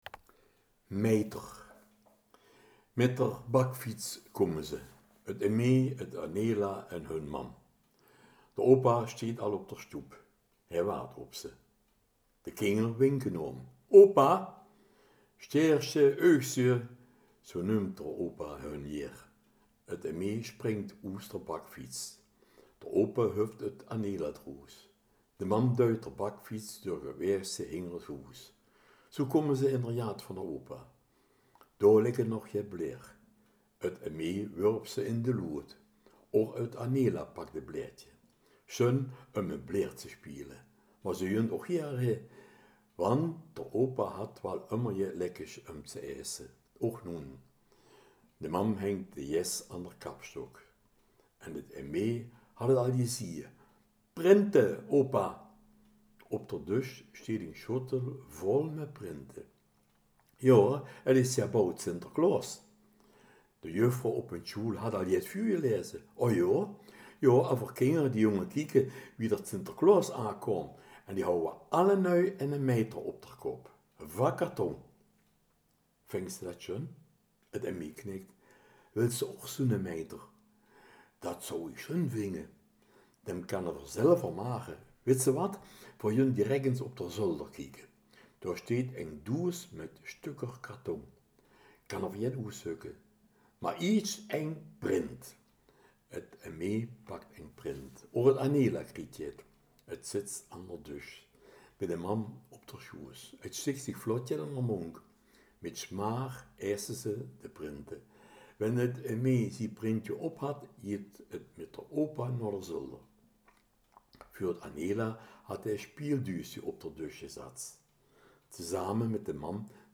in het kerkraads dialekt: gedichten, proza, artikelen en ook gesproken verhalen en columns.